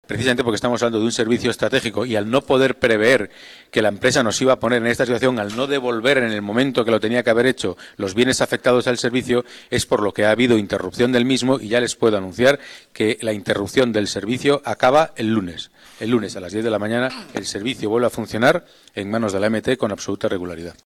Nueva ventana:Declaraciones de Pedro Calvo, delegado de Seguridad y Movilidad